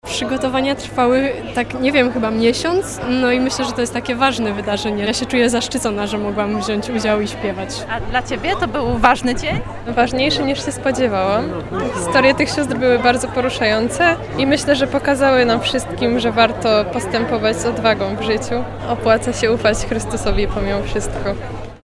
Oprawą muzyczną Mszy Beatyfikacyjnej w Katedrze Wrocławskiej zajął się chór, współtworzony przez siostry zakonne, solistów oraz solistki.
03-sonda-chor.mp3